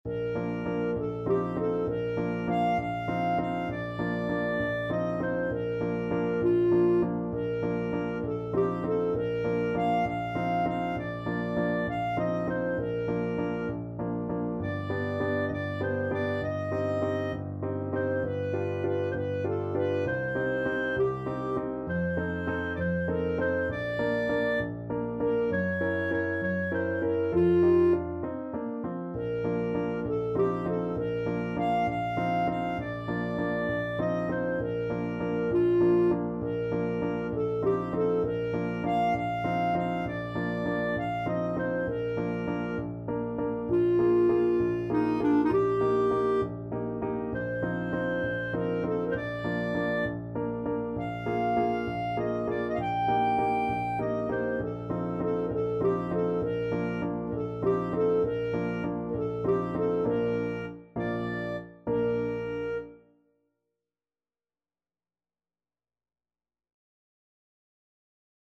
Clarinet
Bb major (Sounding Pitch) C major (Clarinet in Bb) (View more Bb major Music for Clarinet )
6/8 (View more 6/8 Music)
. = 66 No. 3 Grazioso
Classical (View more Classical Clarinet Music)